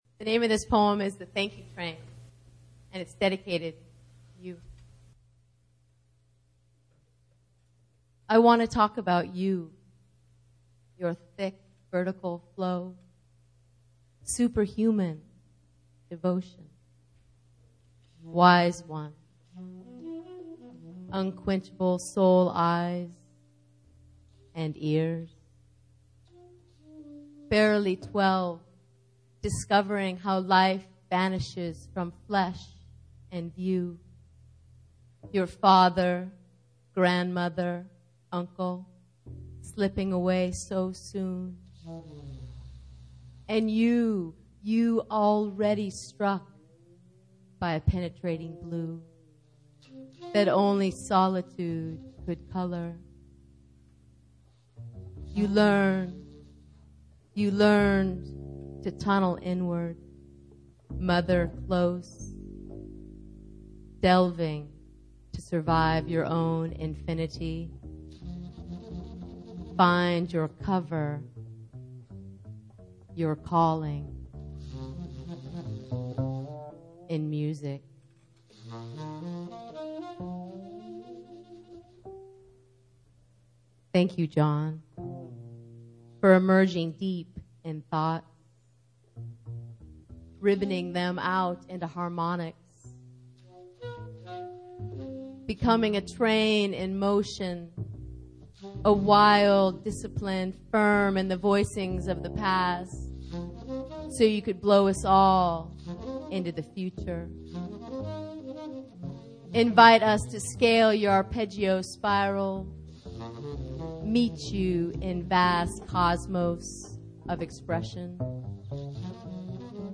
sax
bass
drums